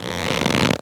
foley_leather_stretch_couch_chair_13.wav